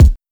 • Smooth Bottom End Kick Single Hit C# Key 101.wav
Royality free bass drum tuned to the C# note. Loudest frequency: 237Hz
smooth-bottom-end-kick-single-hit-c-sharp-key-101-Wja.wav